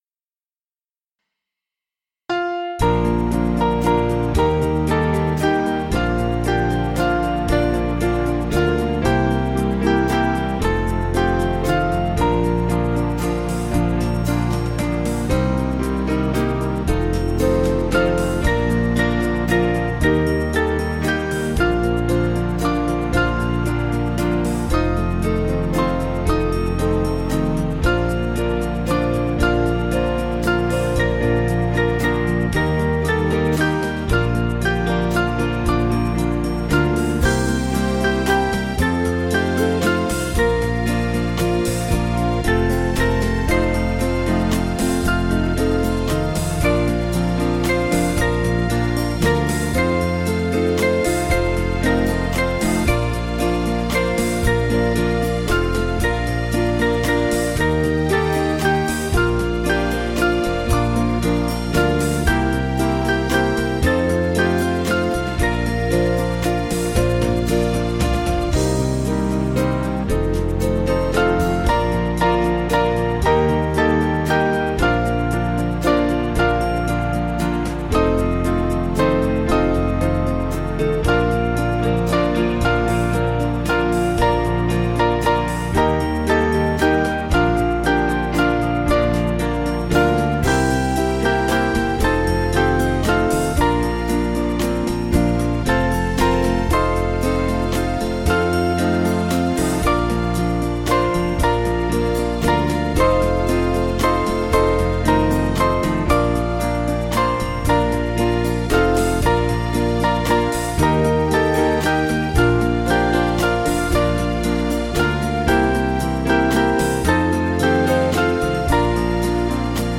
Small Band
(CM)   2/Bb 478kb